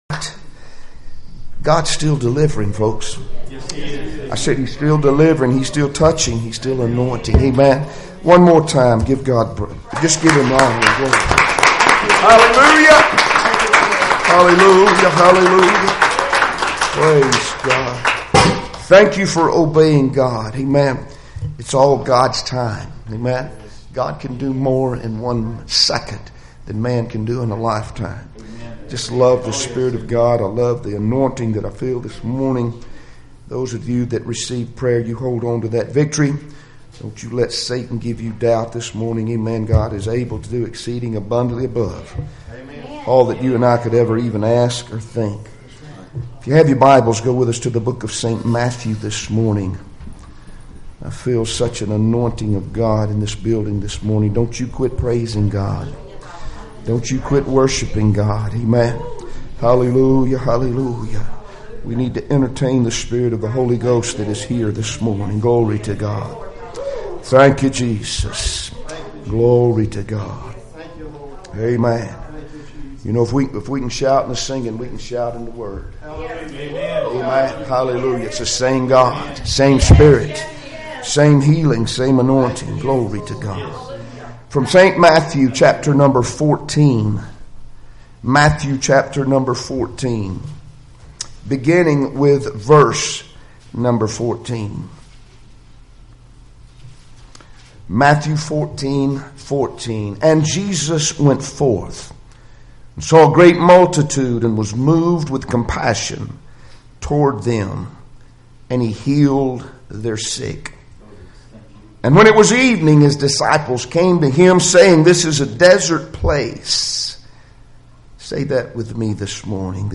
Passage: Matthew 14:14-18 Service Type: Sunday Morning Services Topics